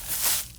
Berry and Bush sounds
Bush.wav